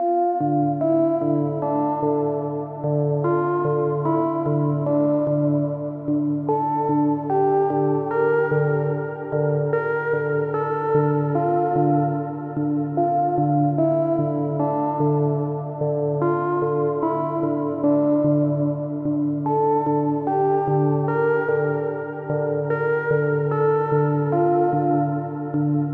标签： 148 bpm Trap Loops Piano Loops 4.36 MB wav Key : G
声道立体声